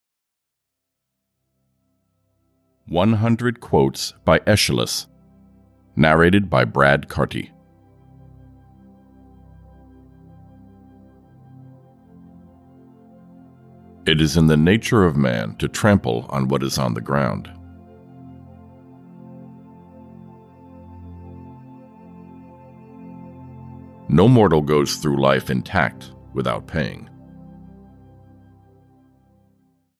Audio kniha100 Quotes by Aristotle: Great Philosophers & their Inspiring Thoughts (EN)
Ukázka z knihy